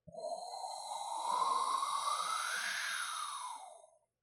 F41 >Wind blowing